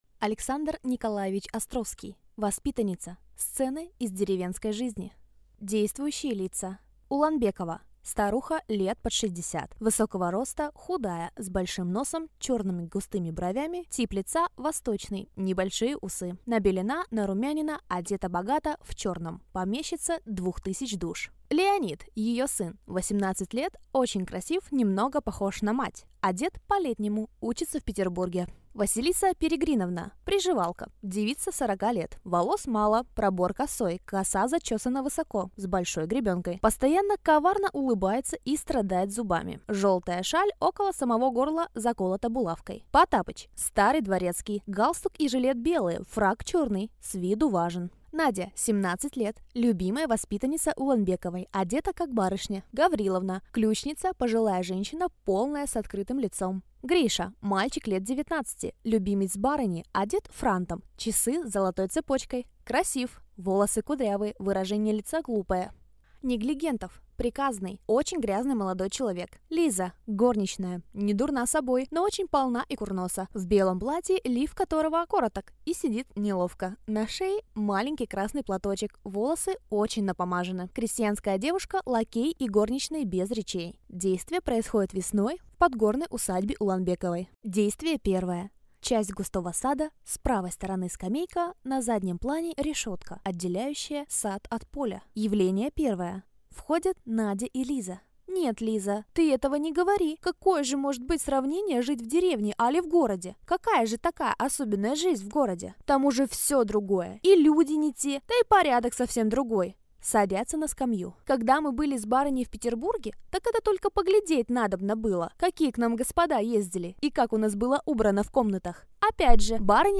Аудиокнига Воспитанница | Библиотека аудиокниг